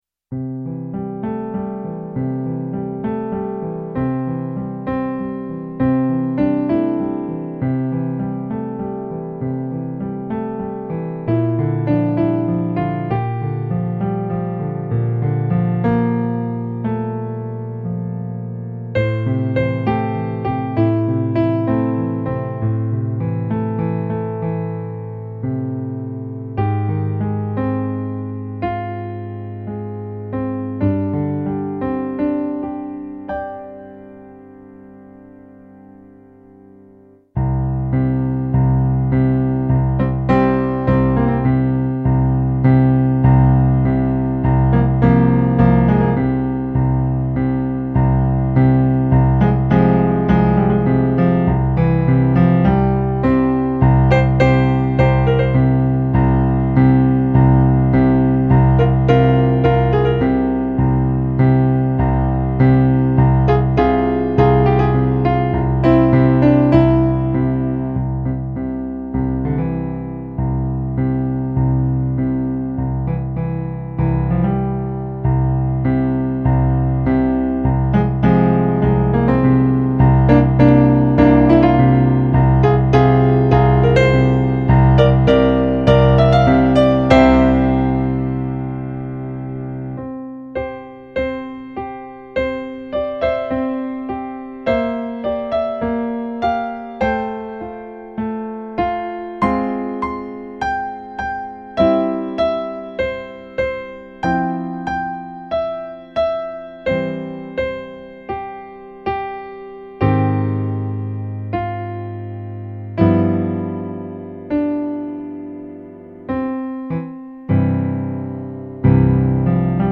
contains eight piano solo arrangements.
rock remix